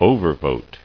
[o·ver·vote]